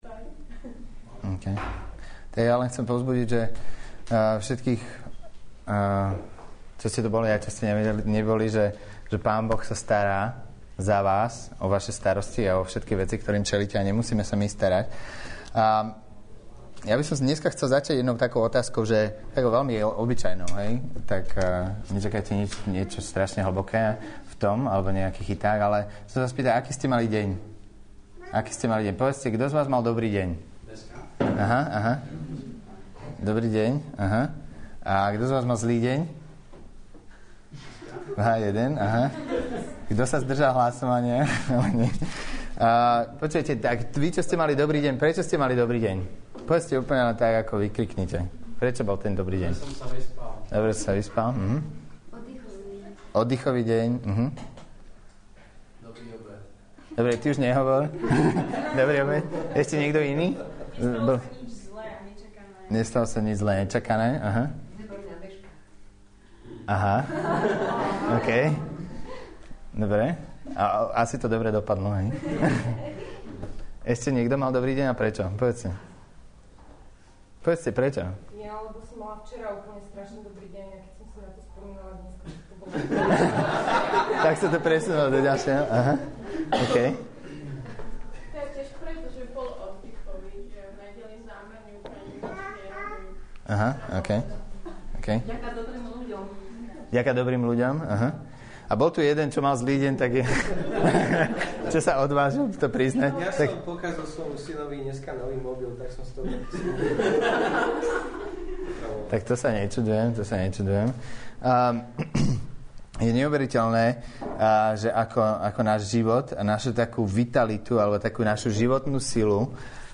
Nahrávka kázne Kresťanského centra Nový začiatok z 11. januára 2009